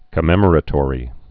(kə-mĕmər-ə-tôrē)